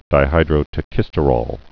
(dī-hīdrō-tə-kĭstə-rôl, -rōl)